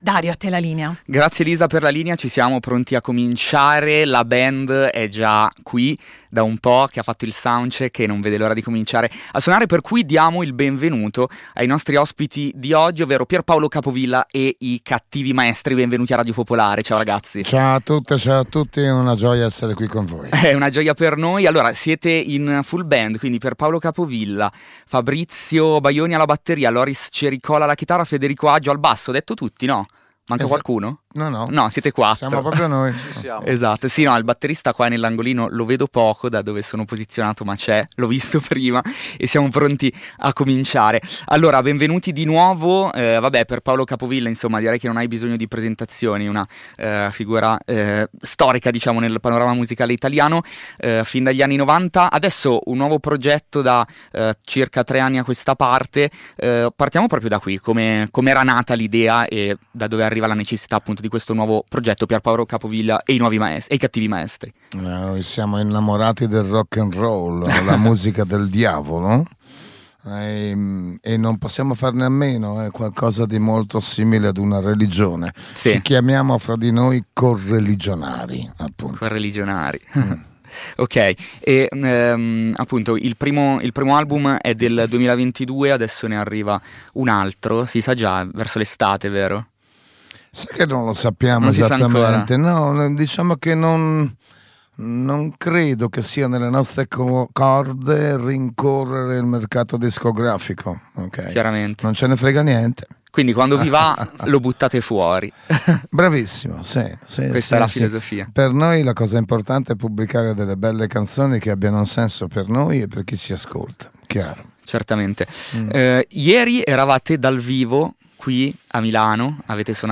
L'intervista
MiniLive